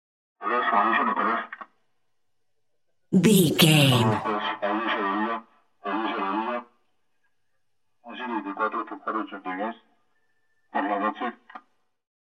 Spanish Policeman Car Megaphone
Sound Effects
urban
chaotic
ambience